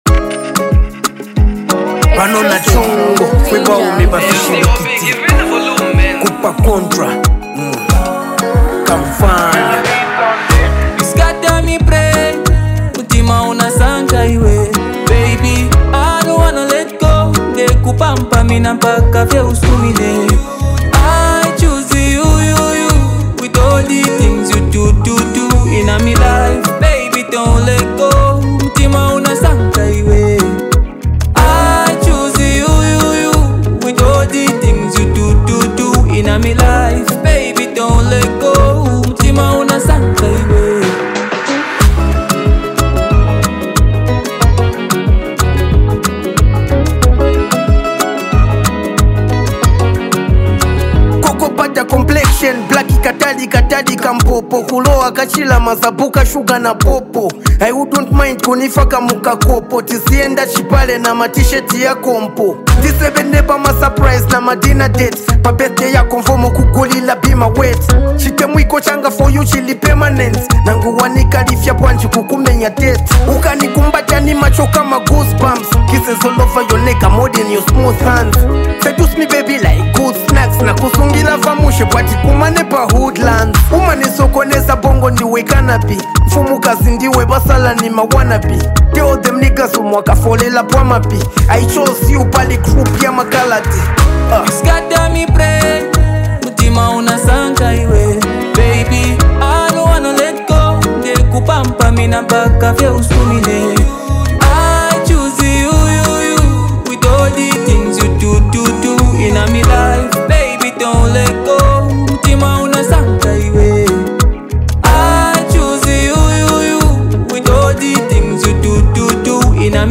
hype, love vibes, and a powerful hook with unique rap flow